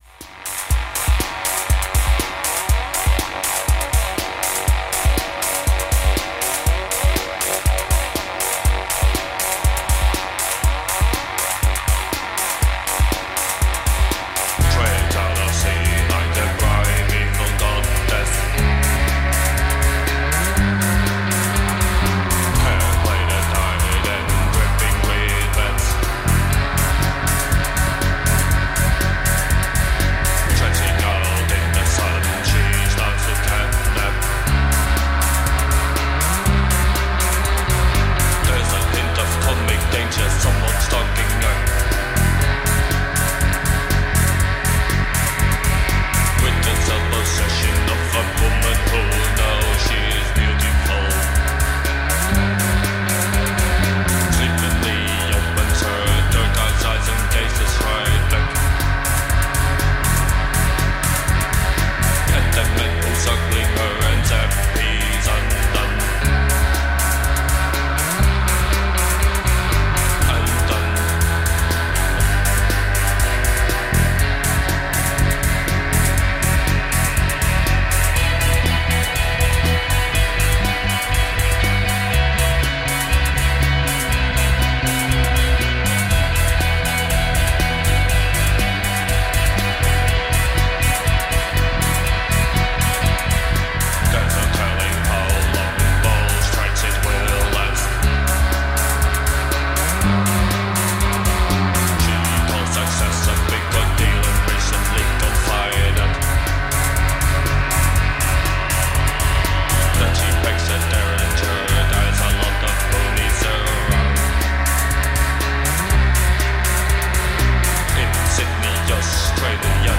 Dutch Post-Punk/Art-Punk/New Wave band
Dutch ultra- Post-Punk .